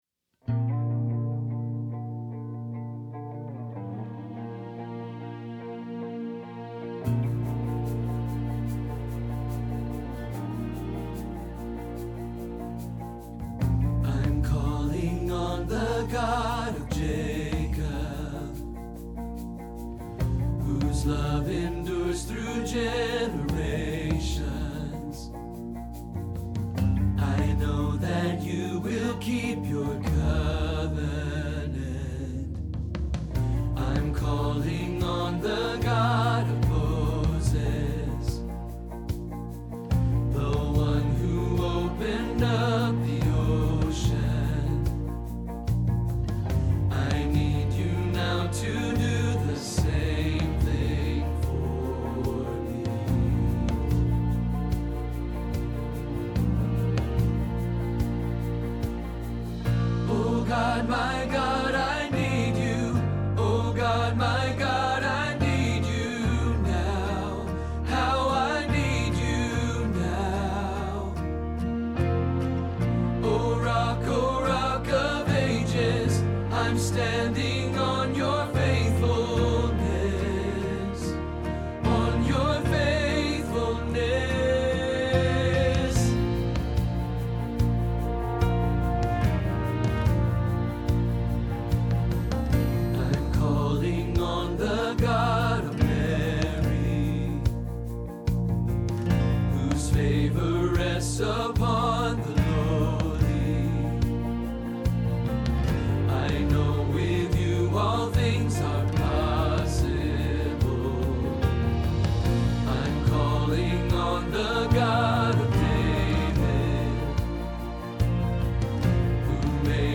Same God – Bass – Hilltop Choir
Same-God-Bass.mp3